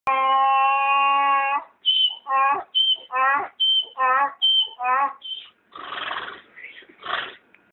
10. Имитация голосом звуков осла